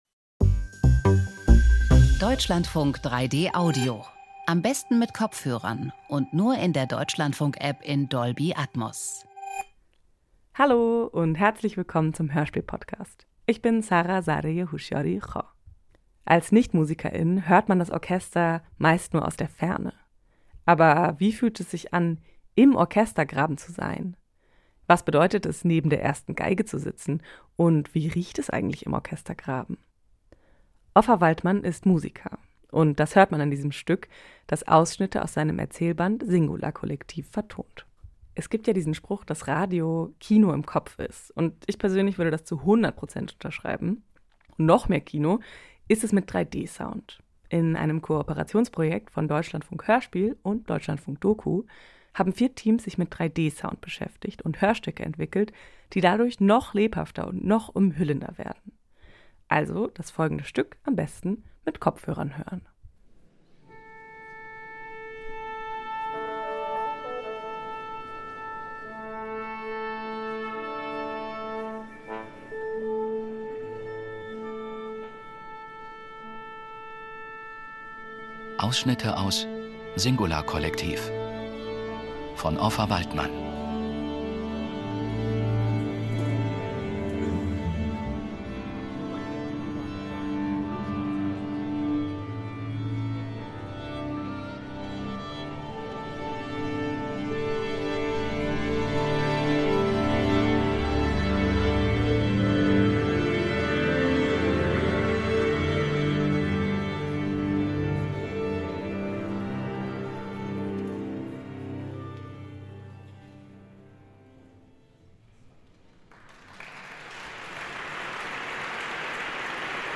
Singularkollektiv - Hörspiele und Dokus in 3D (1/4) ~ Hörspiel Podcast